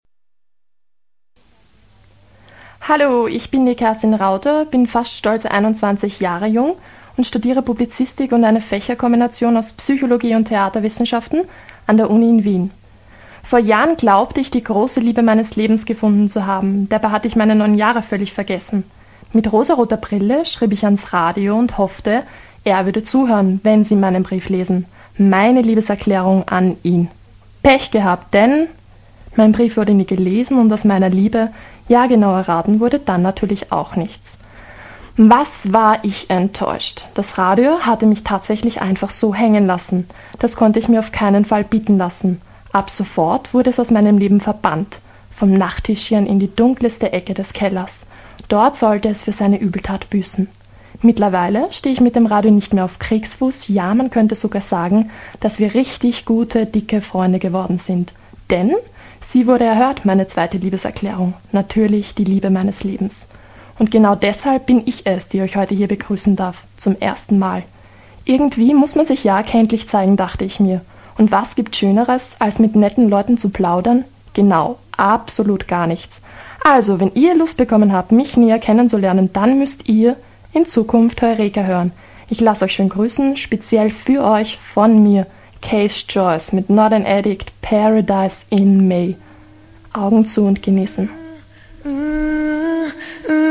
Wir haben reingehört – in unsere allererste Radio-Sendung anlässlich des heutigen Welttags des Radios.